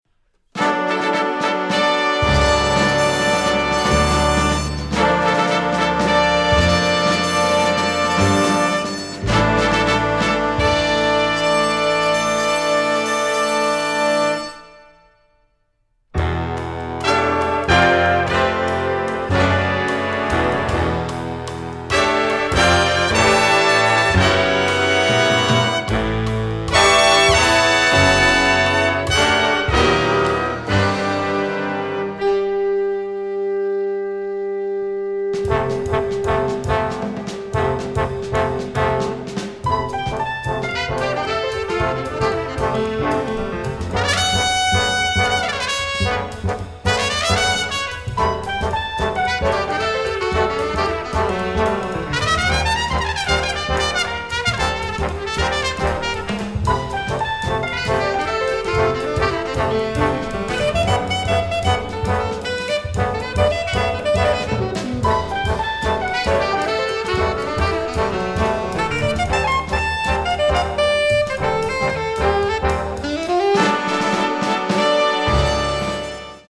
Big Band Charts
Solos: alto 1, trumpet 1